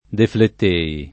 deflett%i]; part. pass. deflesso [defl$SSo] o deflettuto [deflett2to] — cfr. flettere